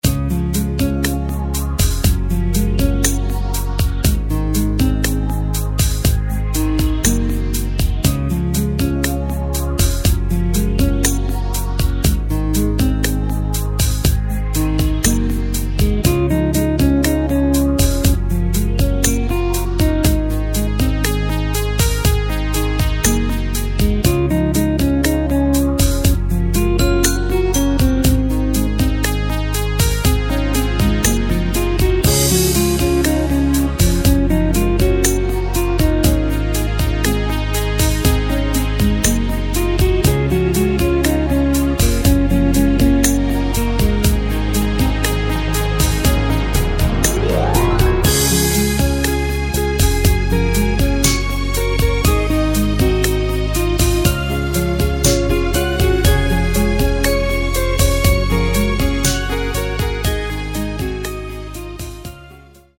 Takt:          4/4
Tempo:         120.00
Tonart:            C#
Pop-Ballade aus dem Jahr 2006!